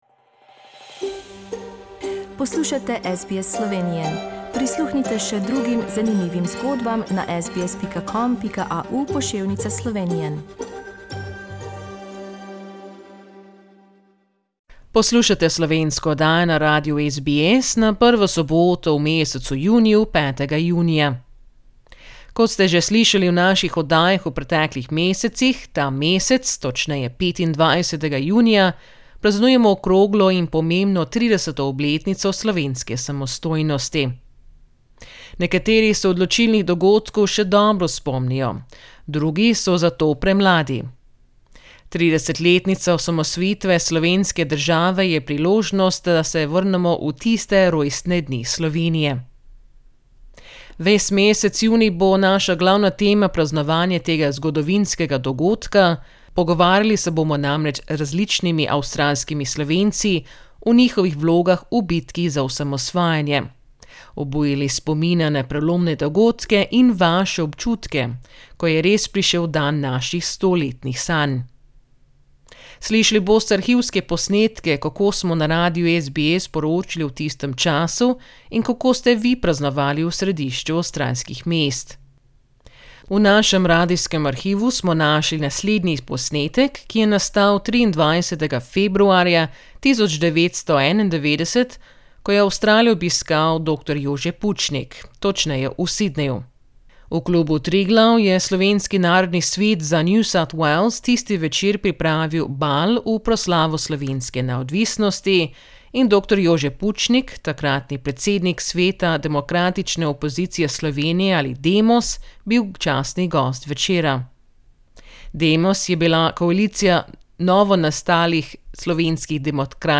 V našem radijskem arhivu smo našli posnetek, ki je nastal 23.februarja, 1991, ko je Avstralijo obiskal dr. Jože Pučnik. V Klubu Triglav v Sydneyu, je Slovenski Narodni Svet za NSW tisti večer pripravil ‘Bal v proslavo slovenske neodvisnosti’ in dr. Jože Pučnik, takratni Predsednik sveta Demos, bil častni gost večera. Prisluhnite odlomkom njegovega govora pred 30 leti v Avstraliji.